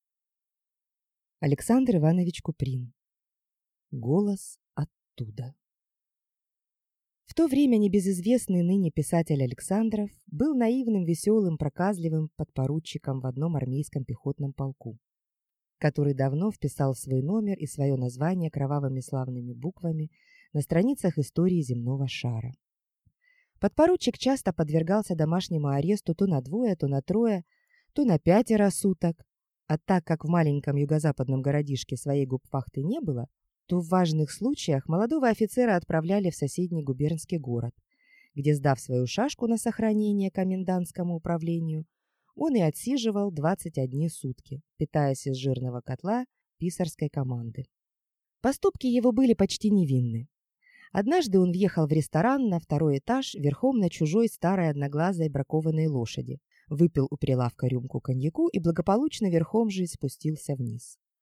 Аудиокнига Голос оттуда | Библиотека аудиокниг